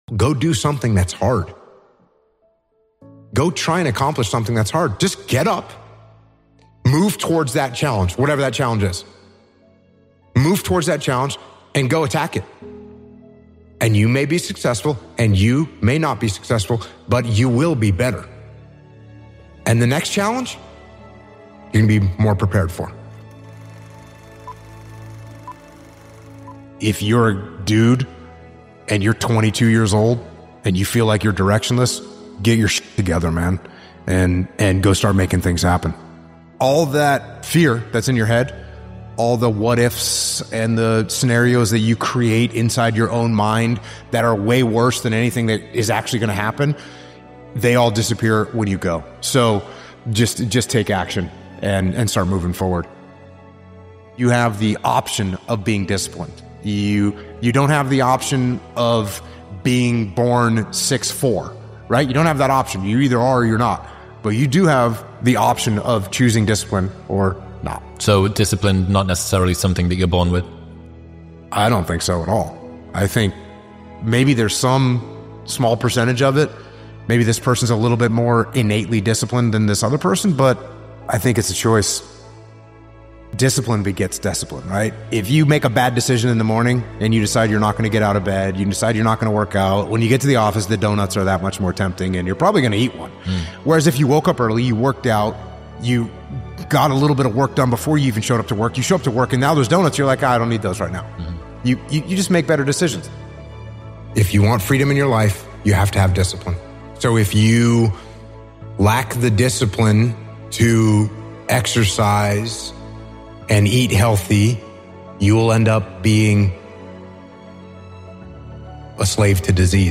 Jocko Willink - Go do something that is hard motivational speech